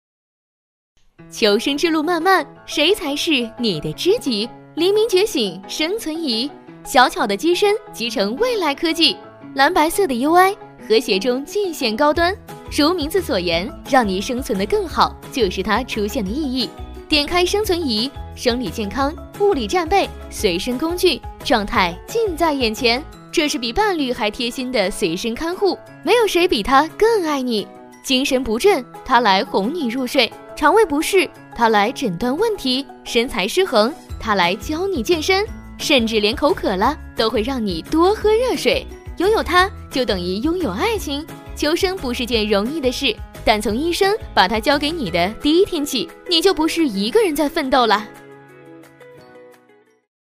女285-产品广告【生存仪 轻松活泼】mp3
女285-产品广告【生存仪 轻松活泼】mp3.mp3